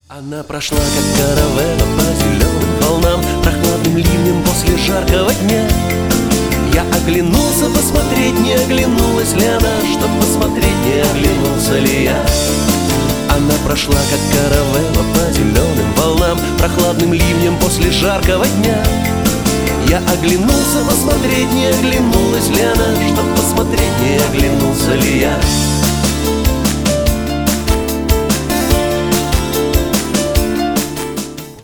поп , ретро